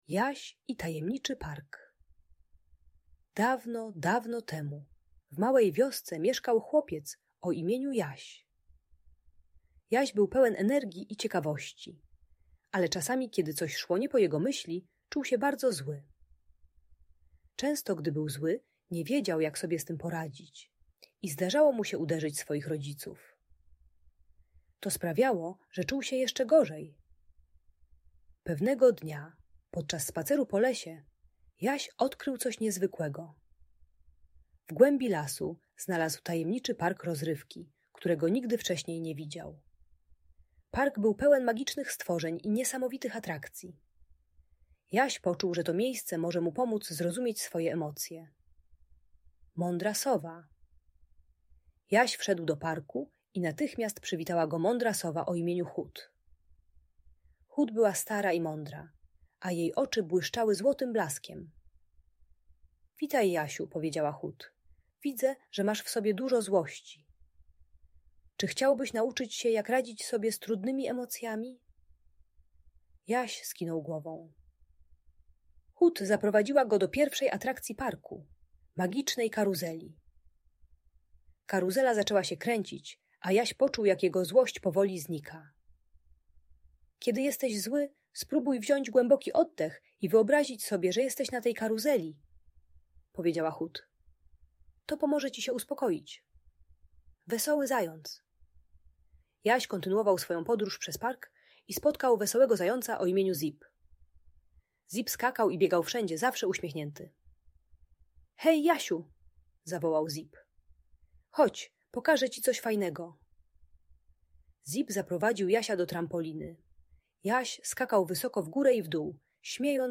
Jaś i Tajemniczy Park - story o radzeniu sobie z emocjami - Audiobajka